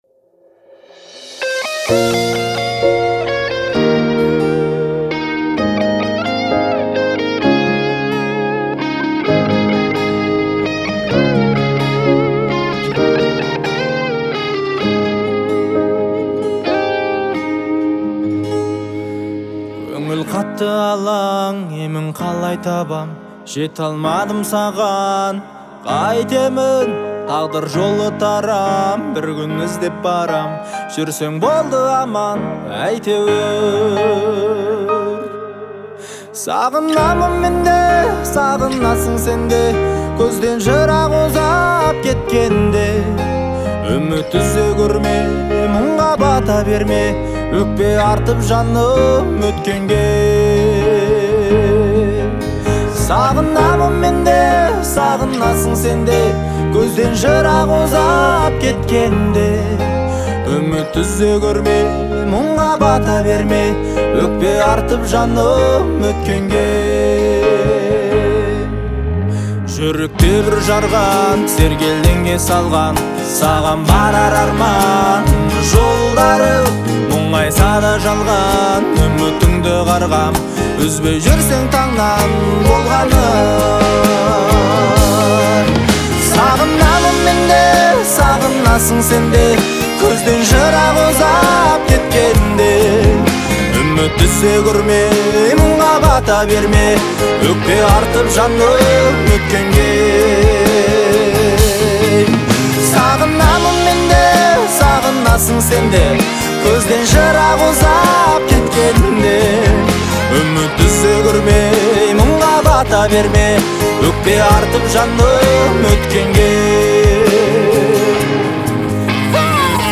трогательная песня